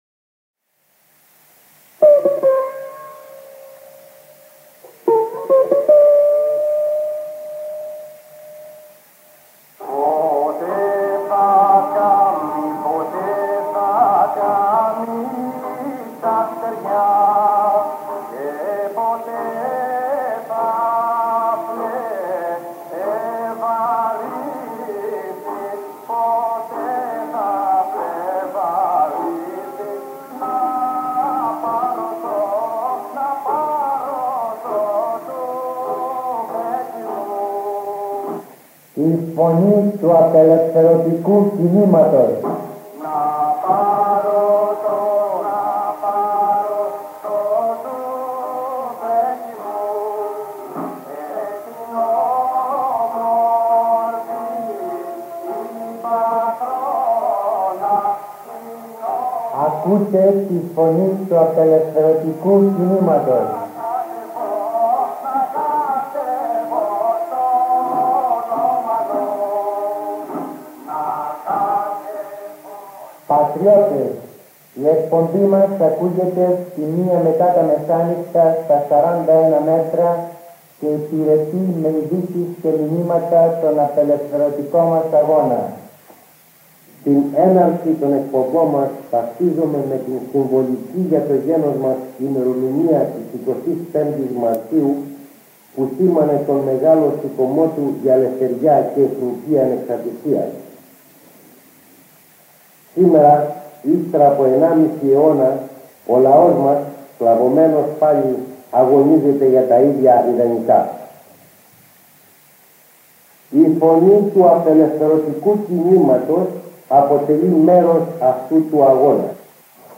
Ακούστε το διάγγελμα του Ανδρέα Παπανδρέου, που εκπέμφθηκε στις 25 Μαρτίου 1972, από τον ραδιοφωνικό σταθμό του Π.Α.Κ. λίγο έξω από την Μπολόνια